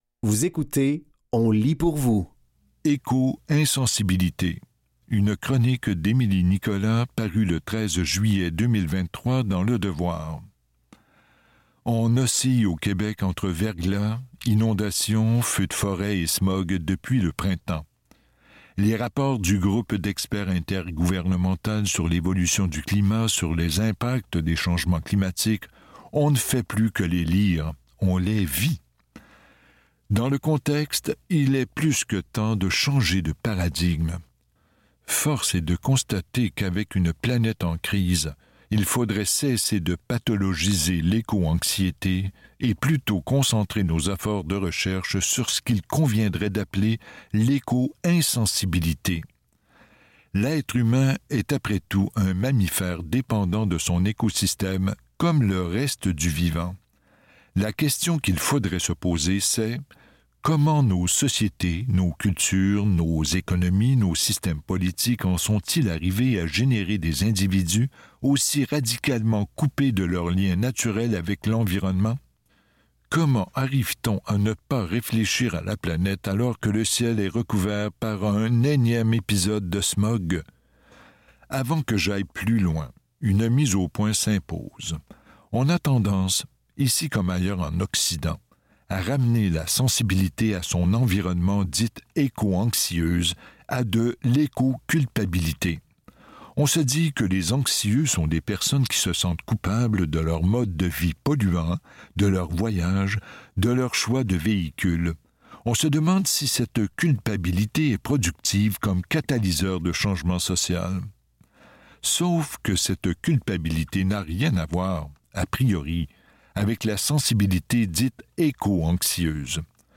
Dans cet épisode de On lit pour vous, nous vous offrons une sélection de textes tirés des médias suivants : Le Devoir, Paraquad, La Source et Fugues.